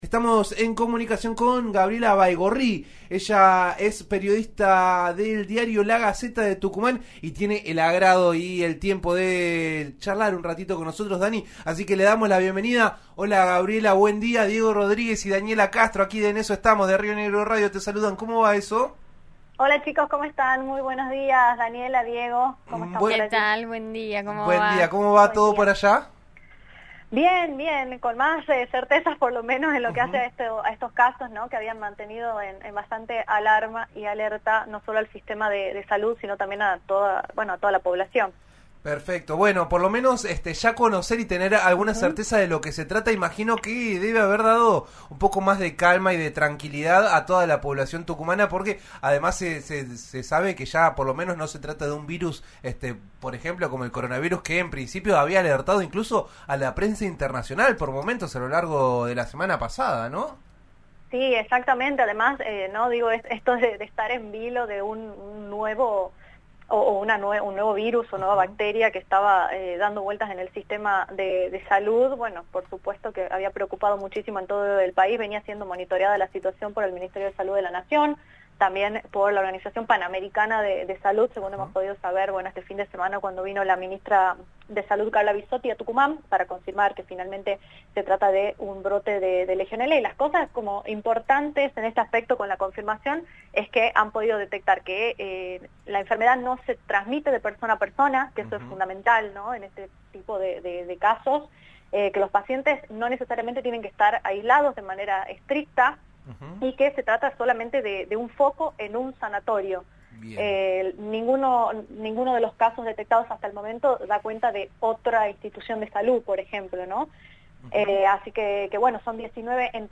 Así lo detalló el ministro de Salud de la provincia al brindar una nueva conferencia de prensa.